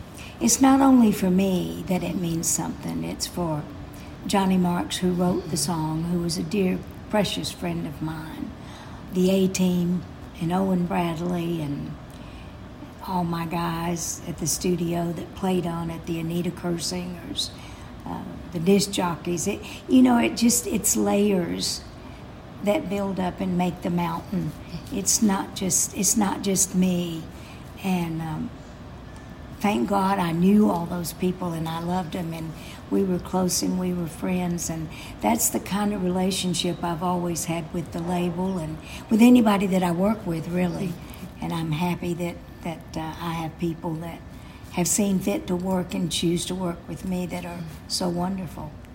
Brenda Lee talks about her reaction to "Rockin' Around the Christmas Tree" hitting the top of Billboard's Hot 100 chart for the first time.
Brenda-Lee-reaction-to-No.-1.mp3